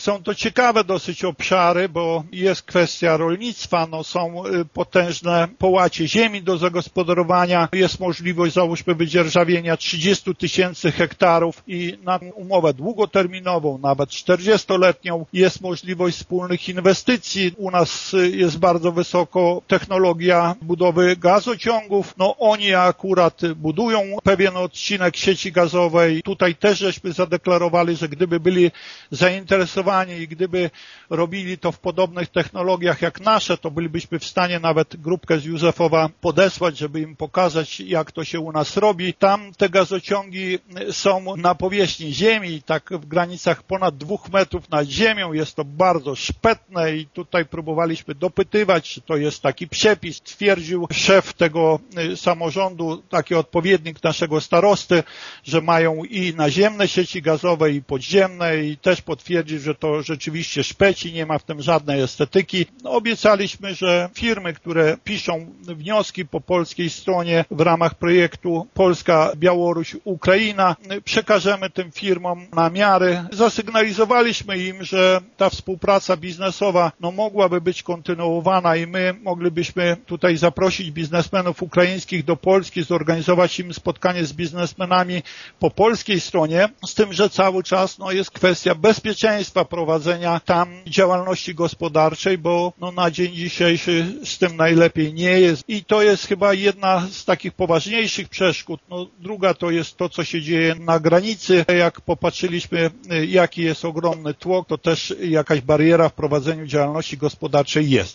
Chociaż najwięcej uwagi podczas ukraińskiej wyprawy poświęcono turystyce i kulturze, burmistrz Dziura nie wyklucza, że w przyszłości obydwie strony mogły nawiązać także współpracę gospodarczą: